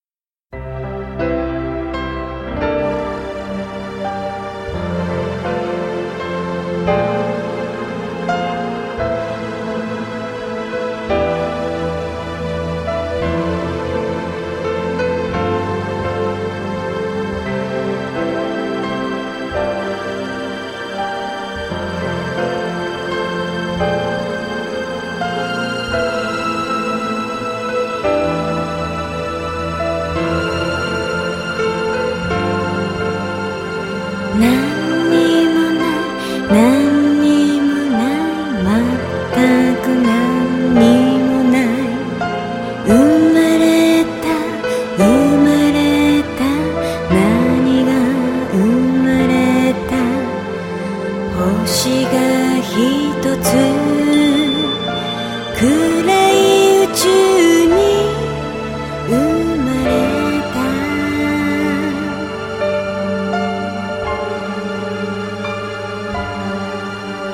スムース・ドリーミー・アンビエンス・カバー